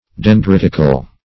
Dendritical \Den*drit"ic*al\
dendritical.mp3